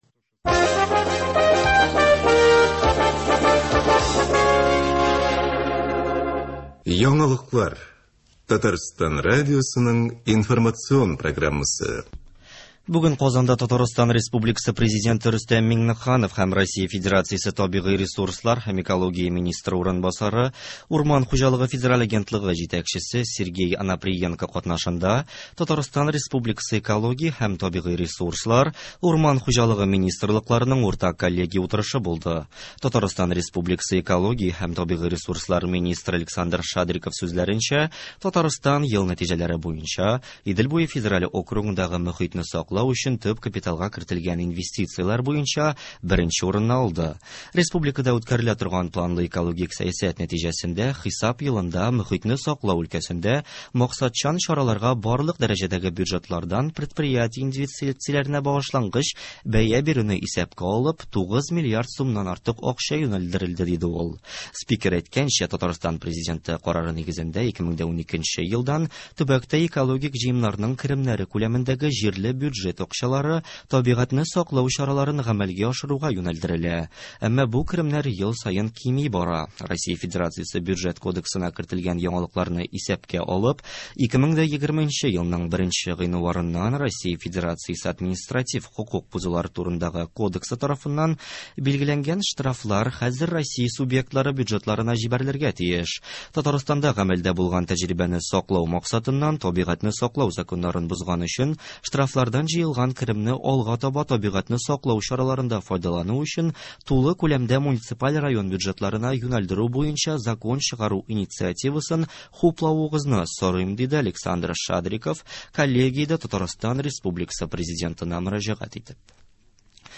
Яңалыклар. 14 гыйнвар.